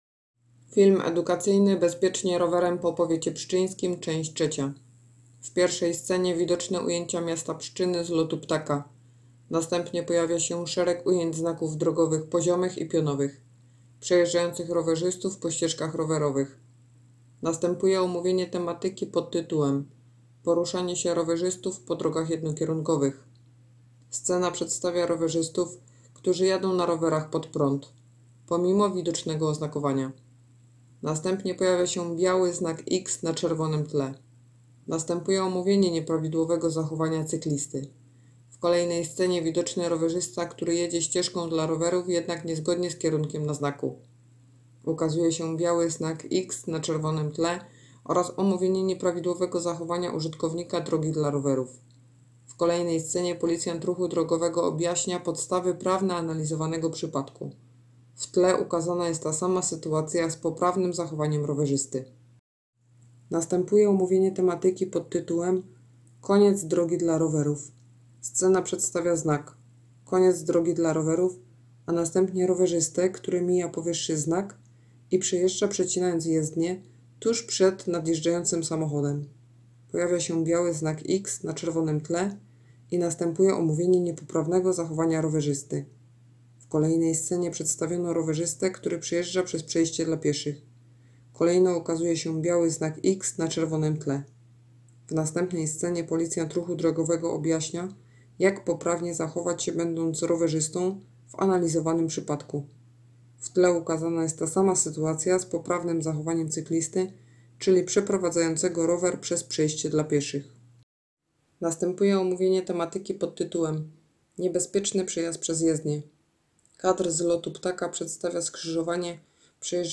Całą akcję wspiera Jarosław Juszkiewicz, dziennikarz radiowy, lektor, którego głos jest znany z popularnej nawigacji.
Nagranie audio Audiodeskrypcja_Bezpiecznie_rowerem_po_powiecie_pszczynskim_czes___3.m4a